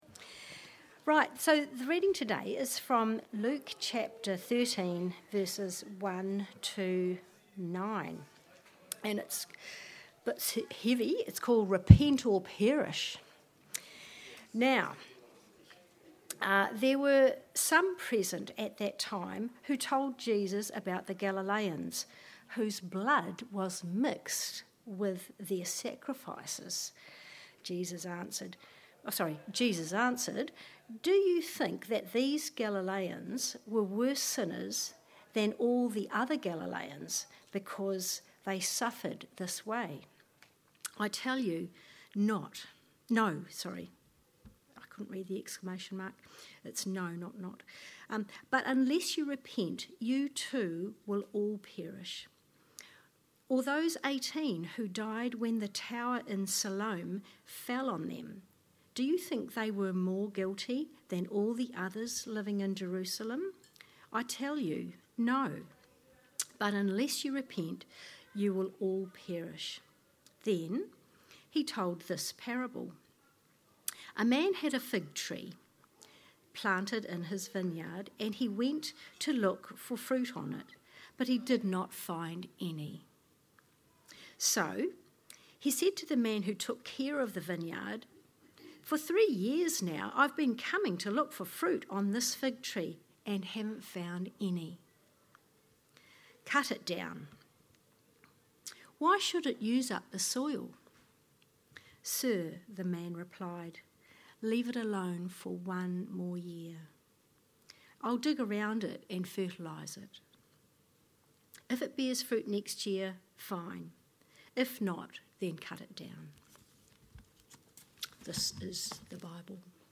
Sermons | All Saints Parish Palmerston North